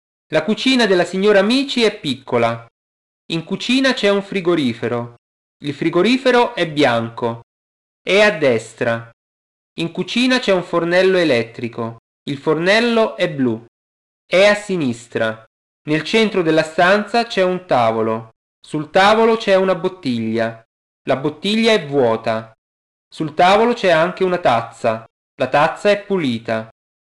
Напишите тот диалог, который произносит диктор, на итальянском языке.